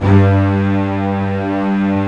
Index of /90_sSampleCDs/Roland - String Master Series/STR_Cbs Arco/STR_Cbs3 Bright